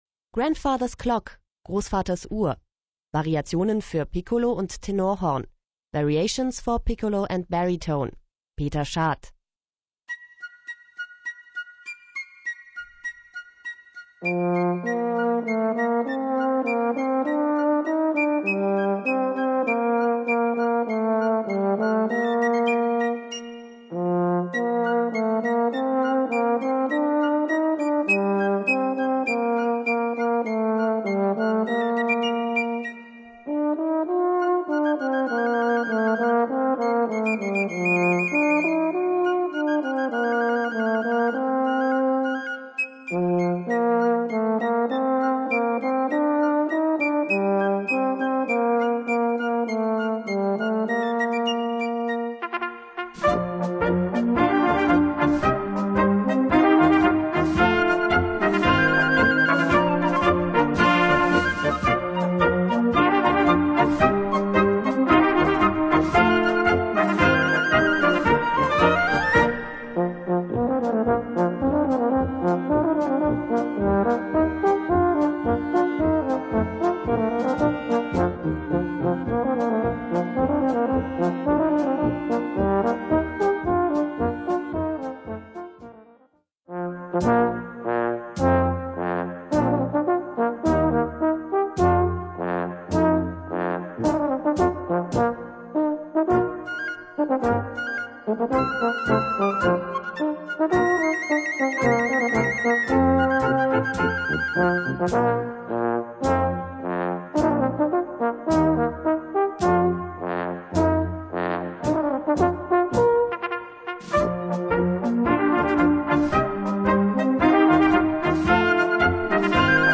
Gattung: Solo für diverse Instrumente und Blasorchester
Besetzung: Blasorchester